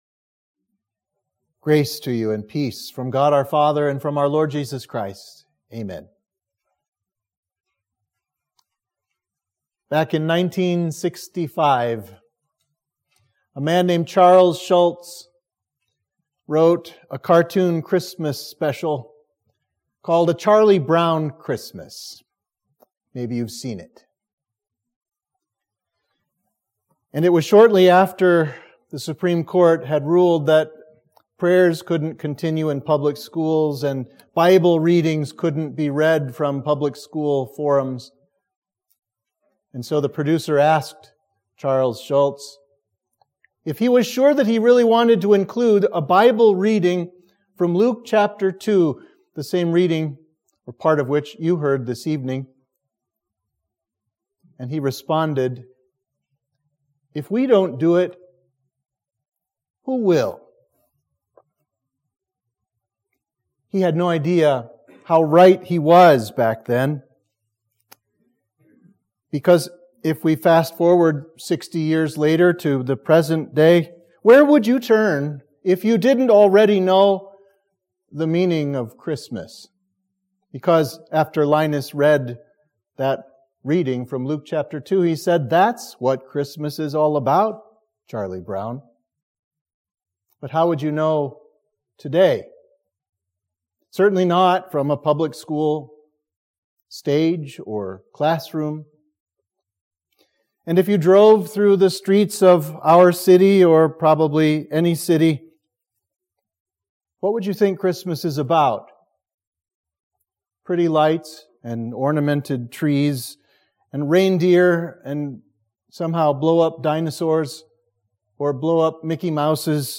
Sermon for the Sunday after Ascension